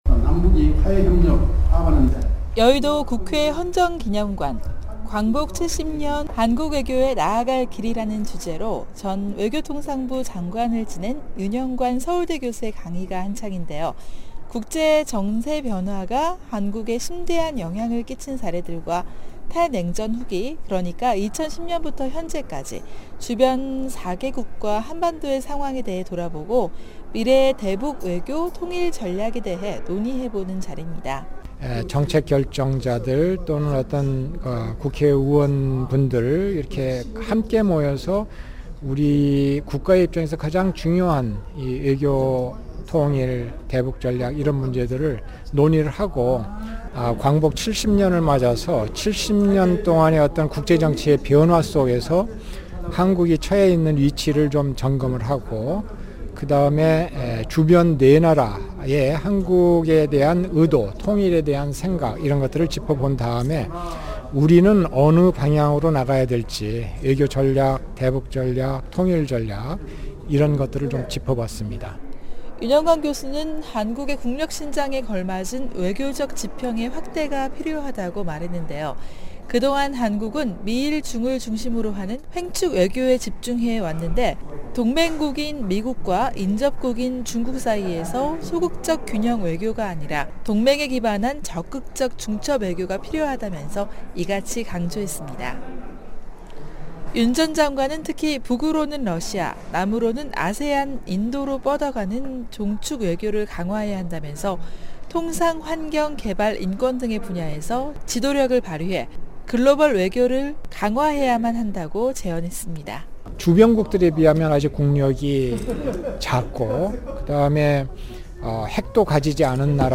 광복 70주년, 한국 외교의 미래 강연
지난 17일, 한국 국회 헌정기념관에서는 ‘광복 70년, 한국 외교의 나아갈 길’이라는 주제로 특별강연이 열렸습니다.